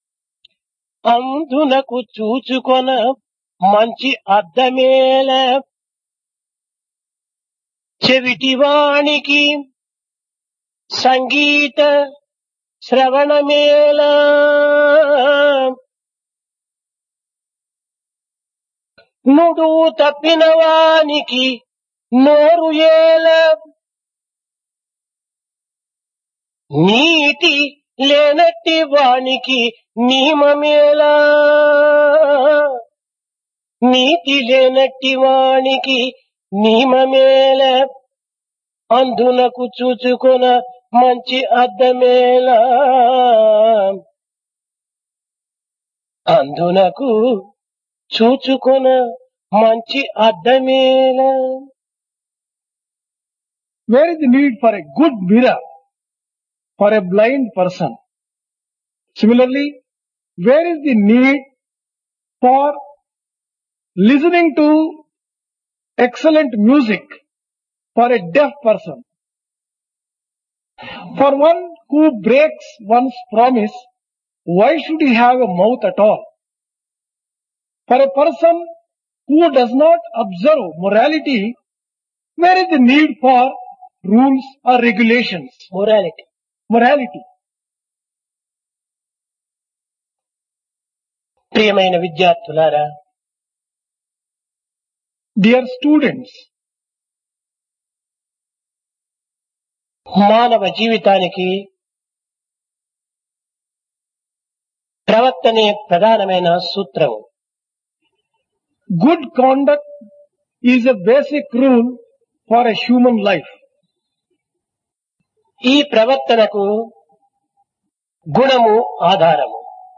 Divine Discourse to University Teachers and Students | Sri Sathya Sai Speaks
Place Prasanthi Nilayam